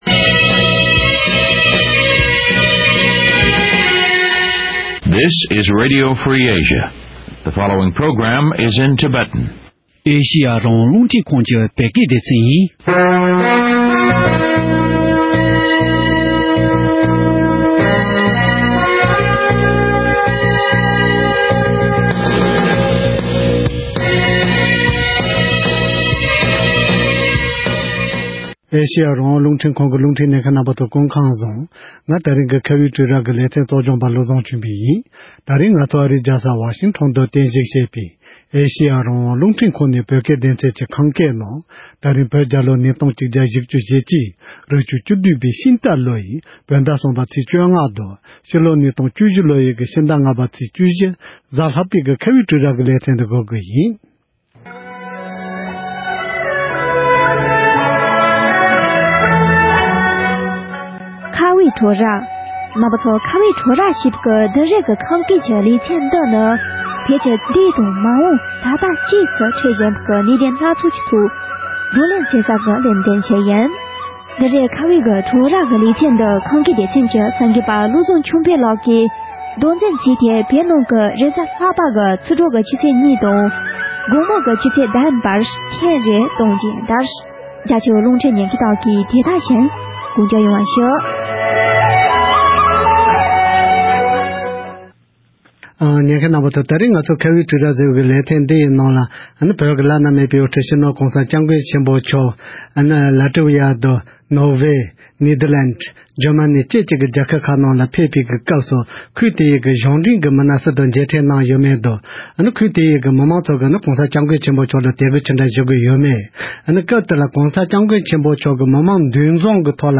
༄༅། །ཐེངས་འདིའི་ཁ་བའི་གྲོས་རྭ་ཞེས་པའི་ལེ་ཚན་ནང་། བོད་ཀྱི་བླ་ན་མེད་པའི་དབུ་ཁྲིད་སྤྱི་ནོར་༸གོང་ས་༸སྐྱབས་མགོན་ཆེ་པོ་མཆོག་ ལ་ཀྲི་ཝེ་ཡ་དང་། ནོར་ཝེ། ནེ་ཐར་ལེནཌ། འཇར་མ་ནི་ བཅས་སུ་ཆིབས་བསྒྱུར་གནང་སྐབས། ཁུལ་དེའི་གཞུང་འབྲེལ་དཔོན་རིགས་སུ་དང་མཇལ་མཕྲལ་གནང་ཡོད་མེད། ཁུལ་དེའི་མི་མང་རྣམས་ཀྱིས་༸གོང་ས་མཆོག་ལ་དད་གུས་ཇི་འདྲ་ཞུ་ཀྱི་ཡོད་མེད་སོགས་ཀྱི་སྐོར་འབྲེལ་ཡོད་ལ་བཀའ་འདྲི་ཞུས་པ་ཞིག་གསན་རོགས་གནང་།།